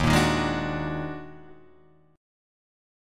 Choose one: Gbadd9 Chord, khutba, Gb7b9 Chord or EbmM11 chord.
EbmM11 chord